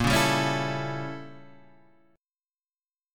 A7/A# chord